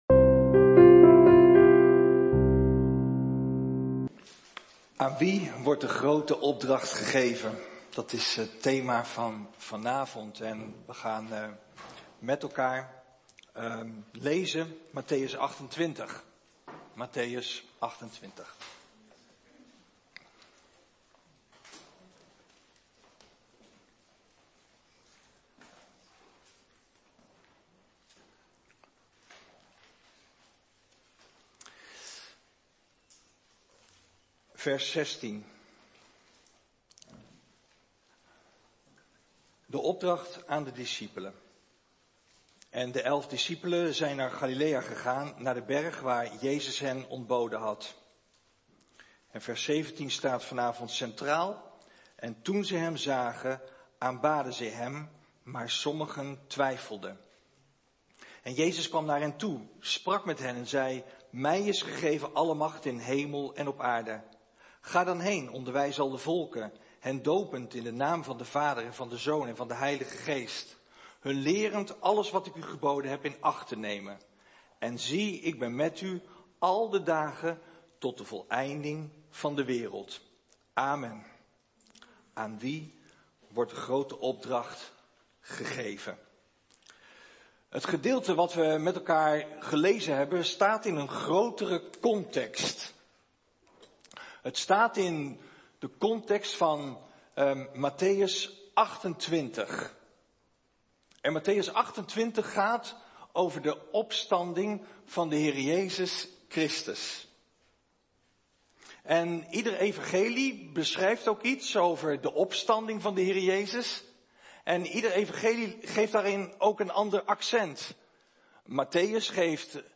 Een preek over 'Aan wie wordt de Grote Opdracht gegeven?'.